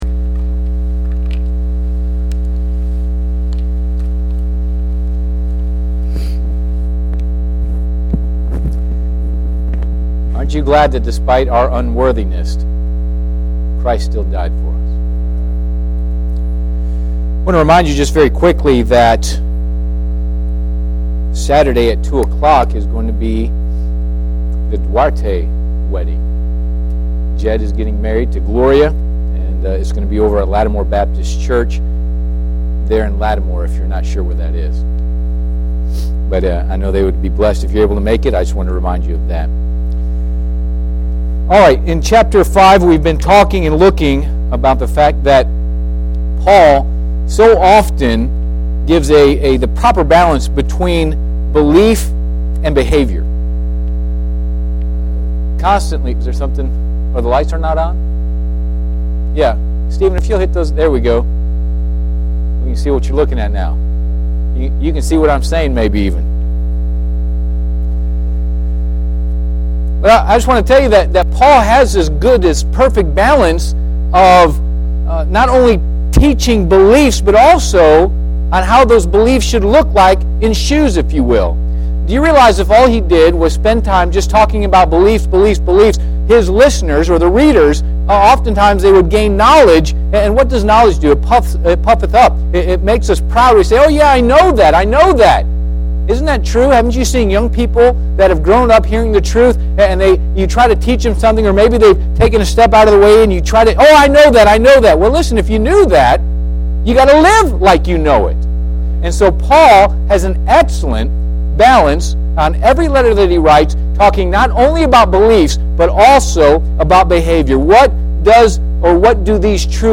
Passage: Galatians 5:19-21 Service Type: Midweek Service Bible Text